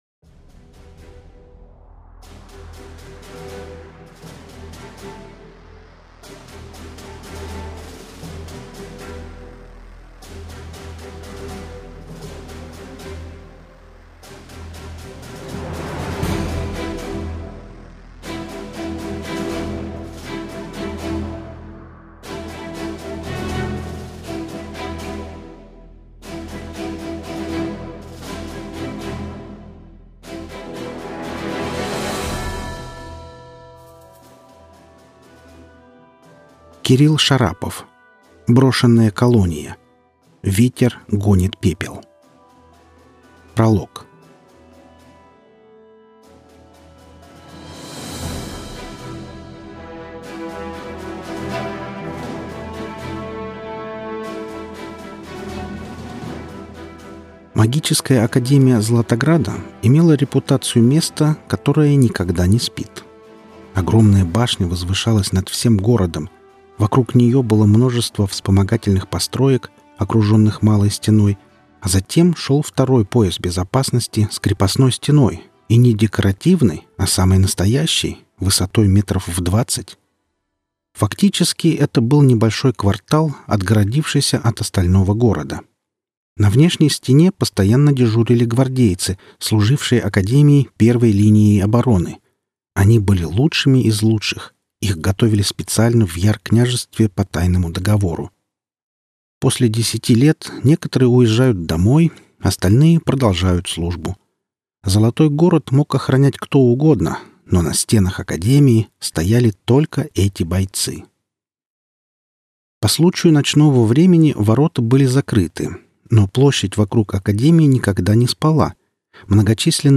Аудиокнига Брошенная колония. Ветер гонит пепел - купить, скачать и слушать онлайн | КнигоПоиск